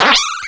pokeemerald / sound / direct_sound_samples / cries / sandile.aif